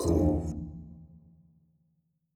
enemy_ambassador_aggro.ogg